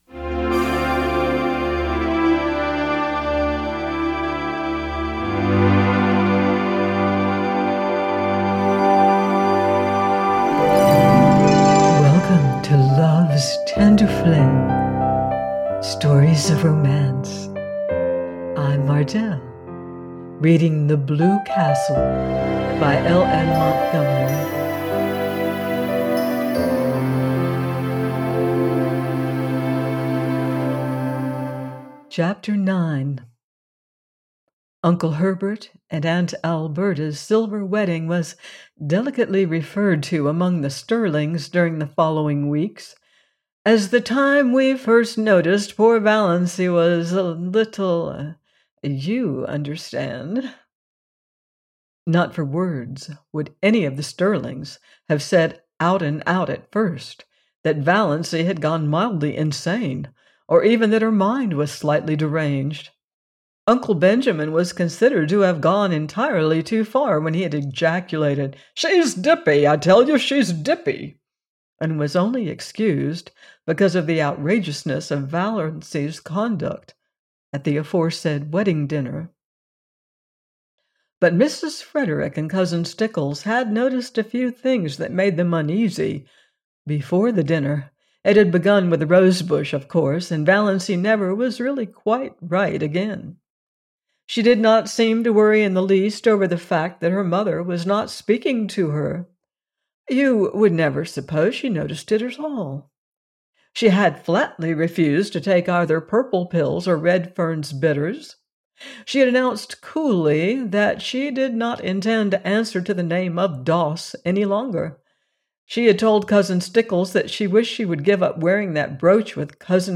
The Blue Castle by L.M. Montgomery - audiobook